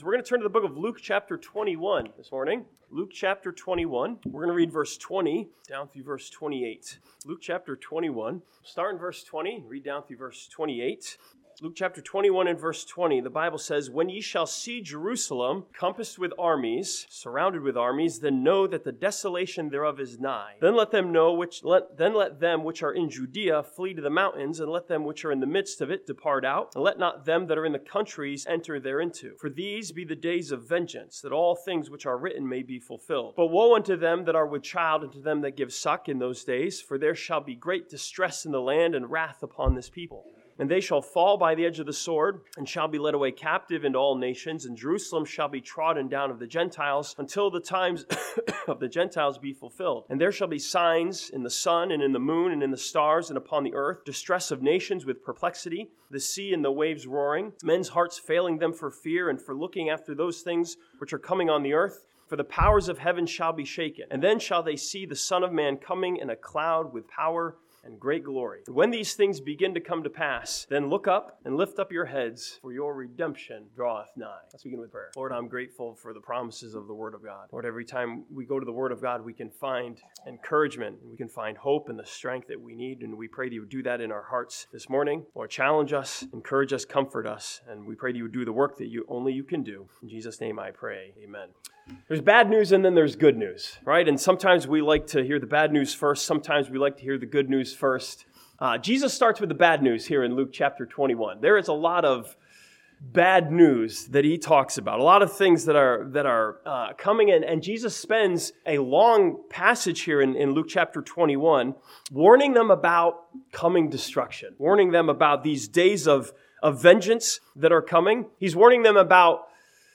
This sermon from Luke chapter 21 looks at the bad news and good news that Jesus gave and encourages us to look up and lift up our heads.